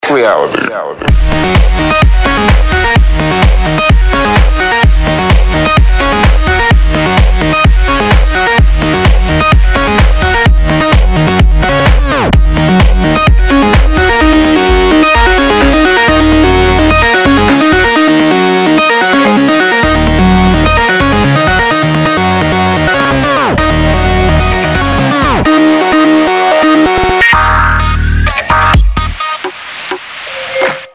club музыка